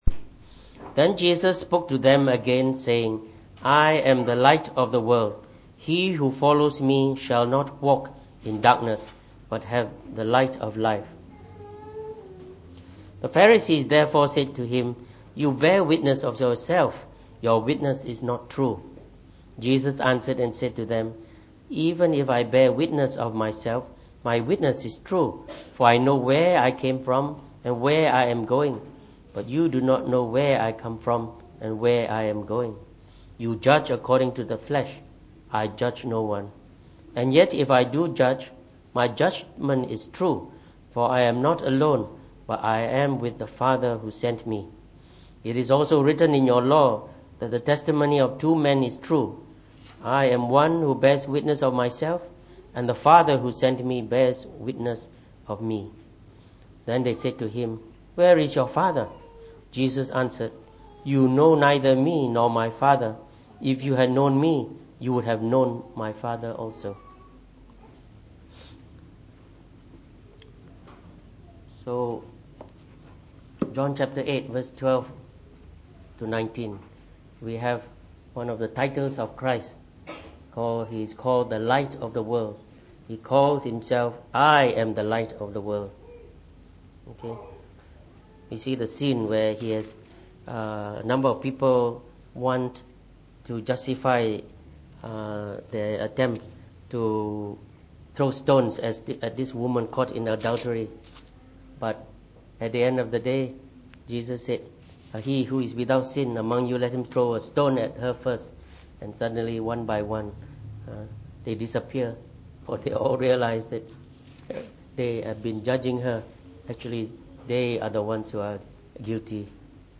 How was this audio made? From our series on the Titles of Jesus Christ delivered in the Evening Service.